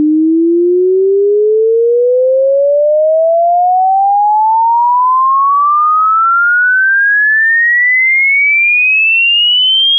consider an audio test tone.
test tone which sweeps from 300 Hz to 3000 Hz in 10 seconds.
sweep.wav